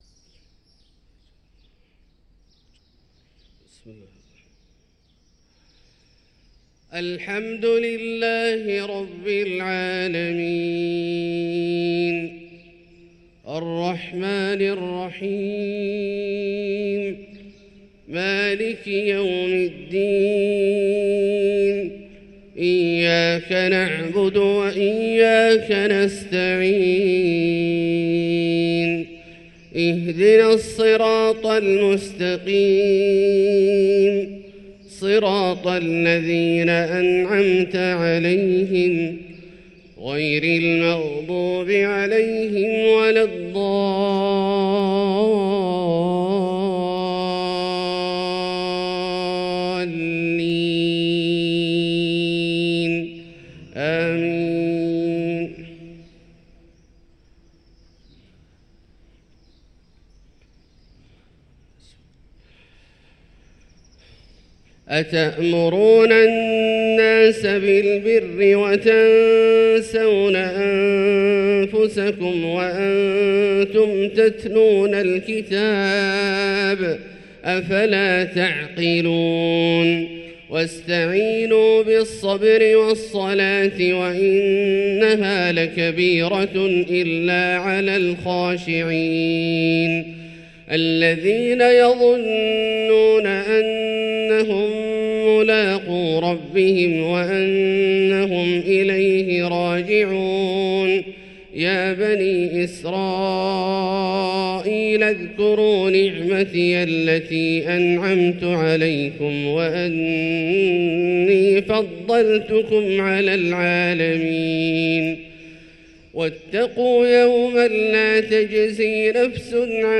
صلاة الفجر للقارئ عبدالله الجهني 21 جمادي الآخر 1445 هـ
تِلَاوَات الْحَرَمَيْن .